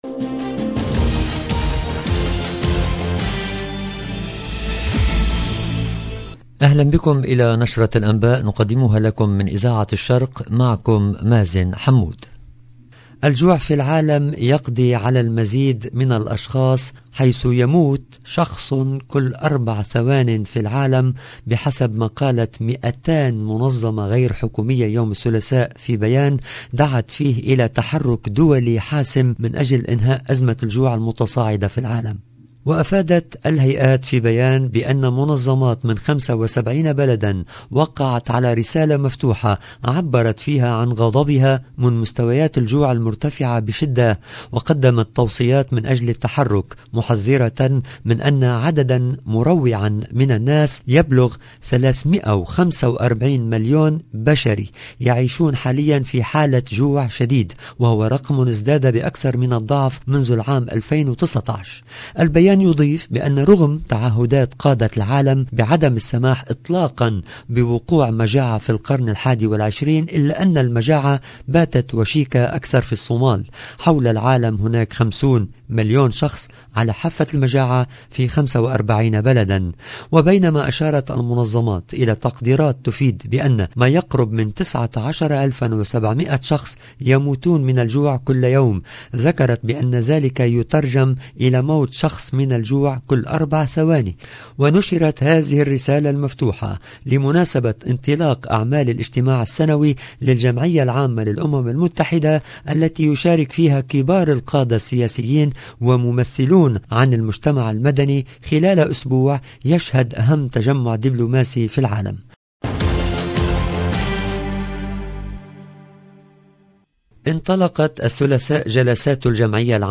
LE JOURNAL DU SOIR EN LANGUE ARABE DU 20/09/2022